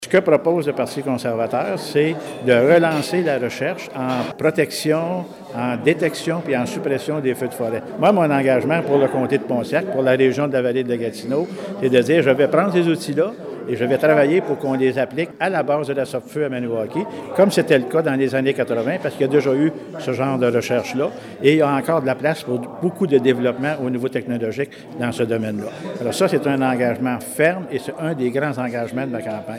a tenu une conférence de presse, mercredi après-midi